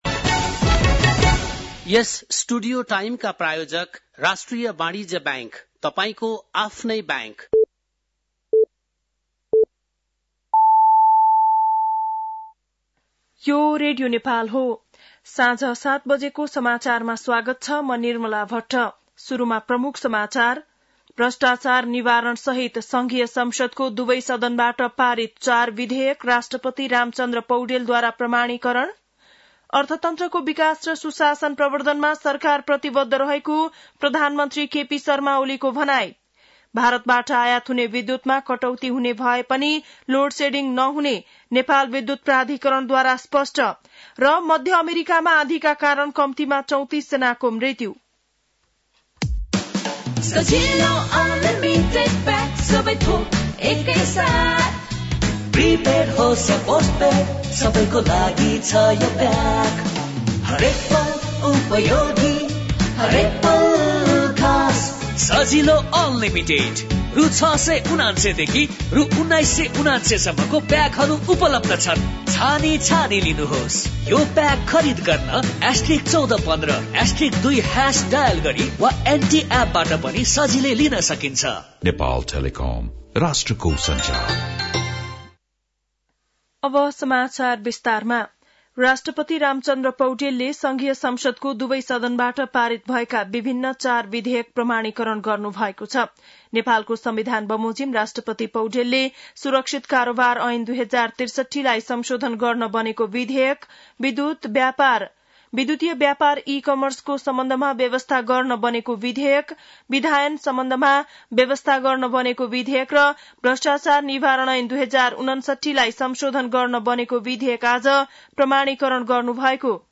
बेलुकी ७ बजेको नेपाली समाचार : ३ चैत , २०८१
7-pm-news-3.mp3